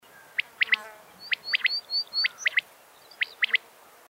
Звуки перепела